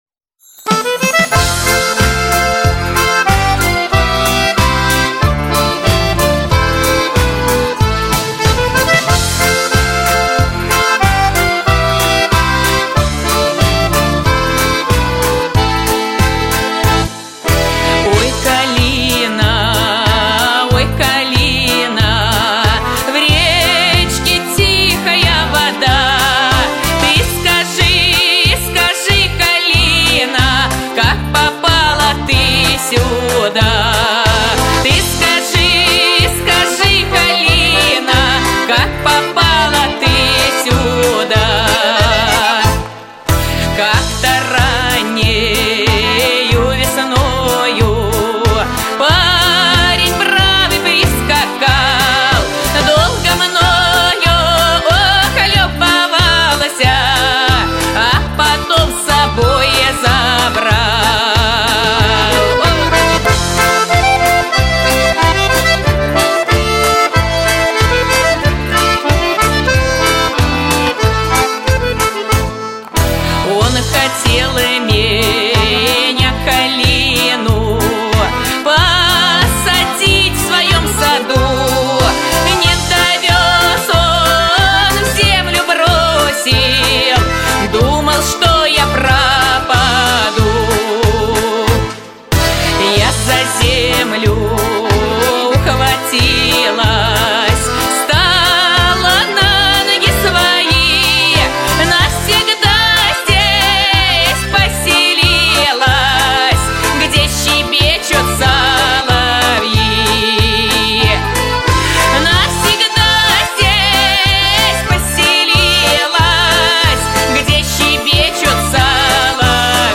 • Жанр: Детские песни
Музыка и слова: народные